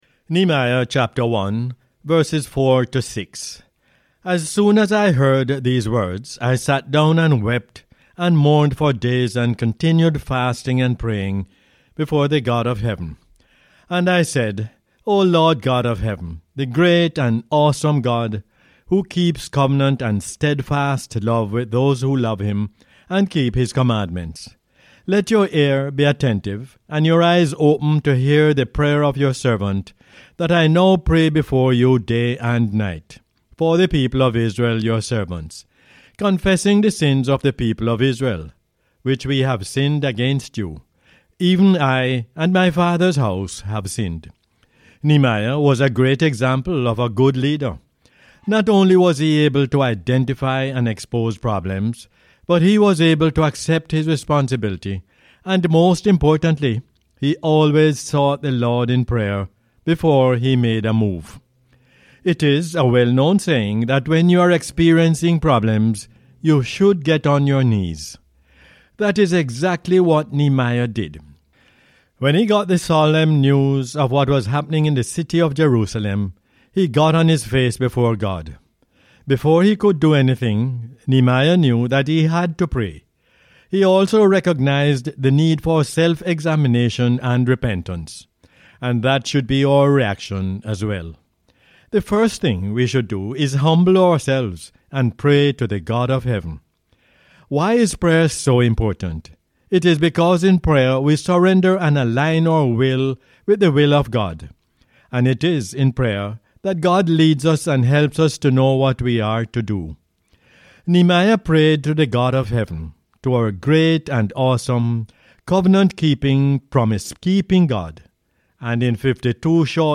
Nehemiah 1:4-6 is the "Word For Jamaica" as aired on the radio on 25 November 2022.